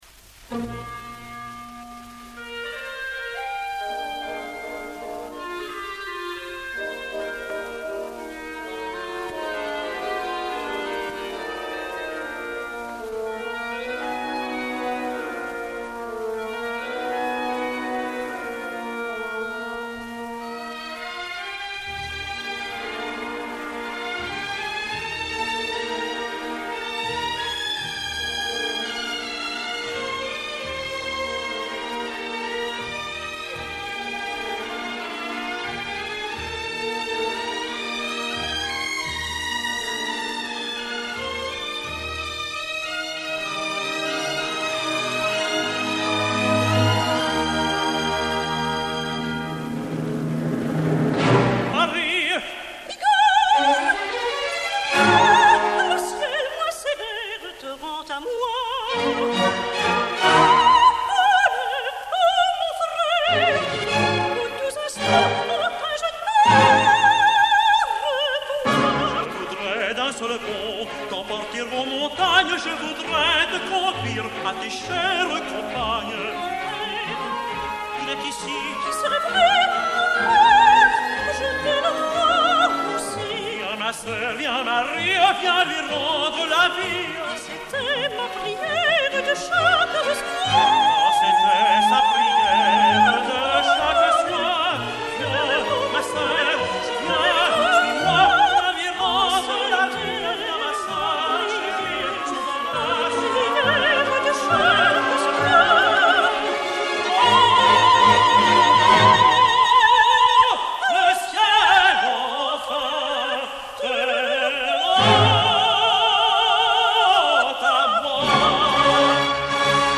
Extraits enregistrés en 1958 (révision musicale d'Henri Büsser) :
Chœurs et Orchestre National de la Radiodiffusion Française dir. Georges Tzipine (chef des chœurs René Alix)
08. Duo (Marie, Igor)